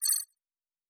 pgs/Assets/Audio/Sci-Fi Sounds/Interface/Data 28.wav at master